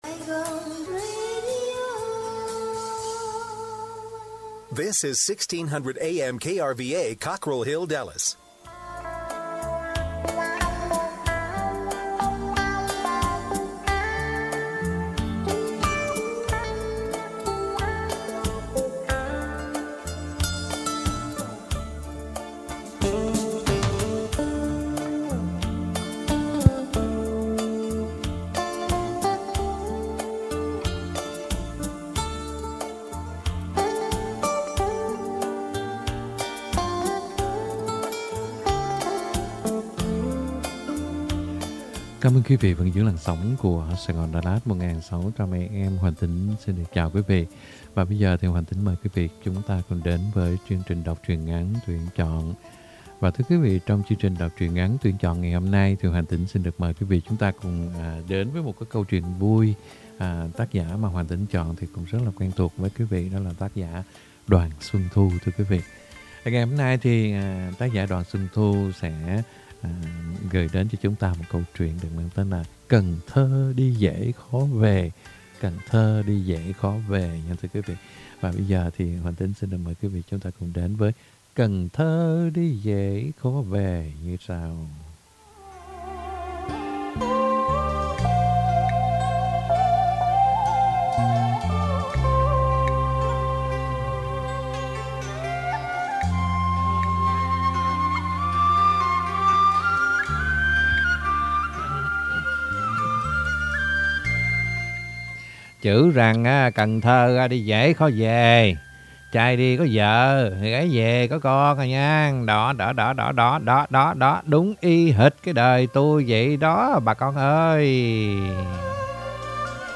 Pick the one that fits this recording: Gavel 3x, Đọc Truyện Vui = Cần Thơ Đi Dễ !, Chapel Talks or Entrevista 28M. Đọc Truyện Vui = Cần Thơ Đi Dễ !